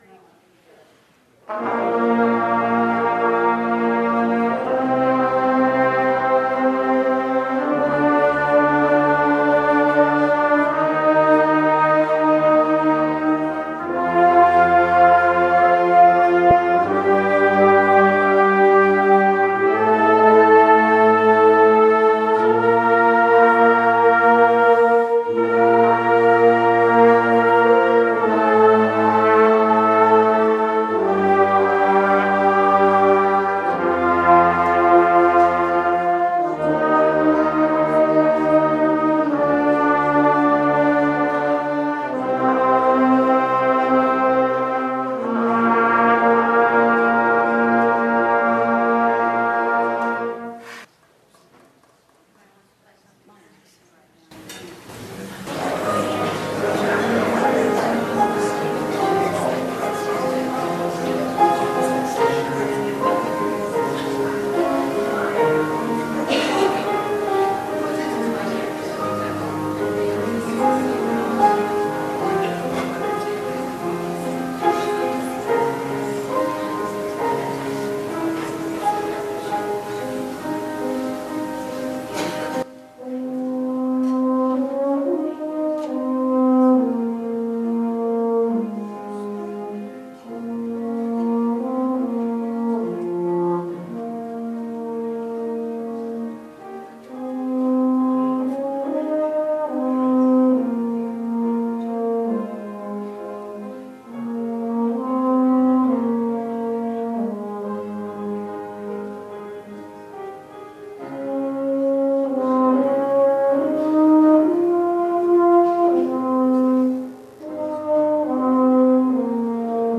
Community Band Concert